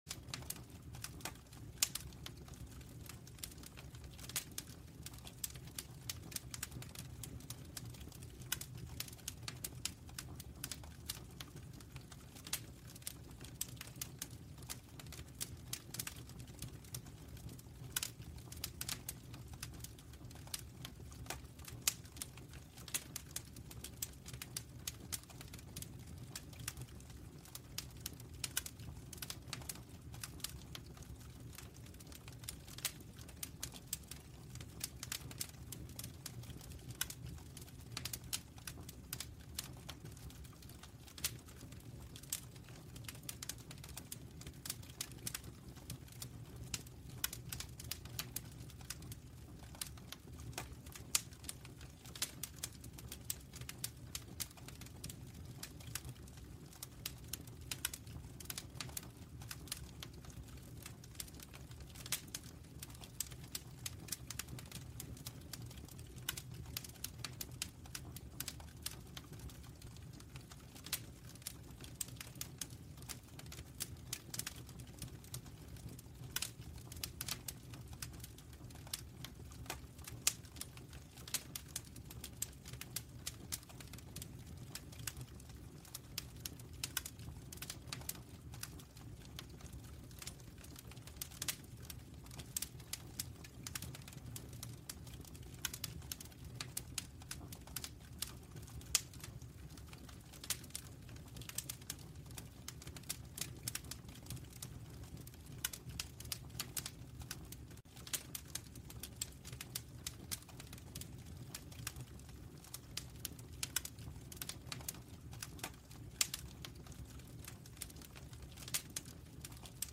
Greenwich Paisible : Pluie Village Calme